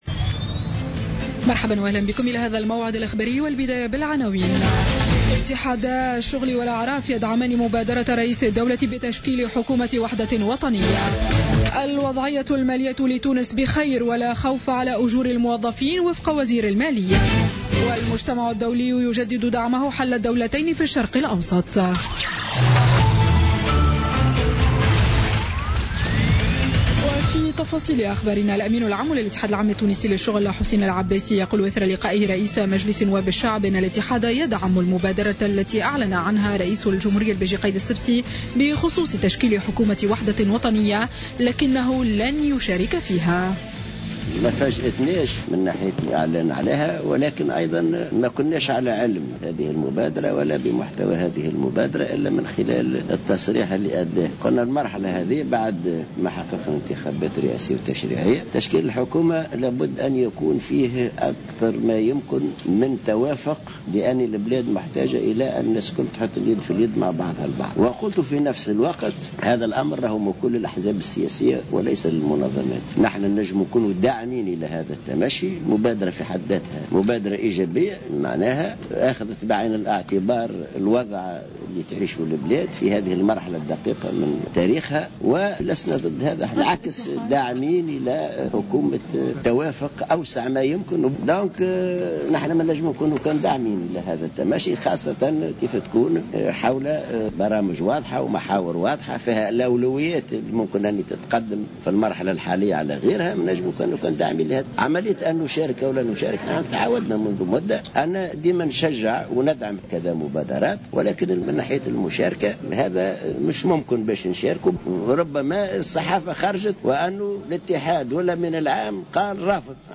نشرة أخبار السابعة مساء ليوم الجمعة 3 جوان 2016